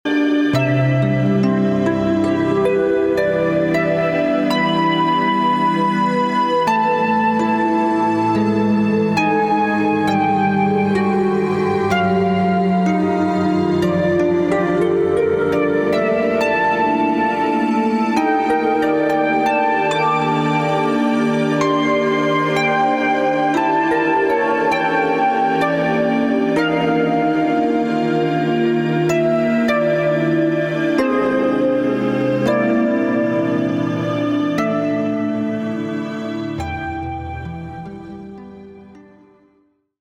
Género: Jazz / Cool Jazz.